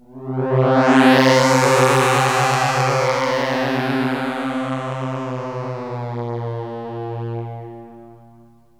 AMBIENT ATMOSPHERES-1 0003.wav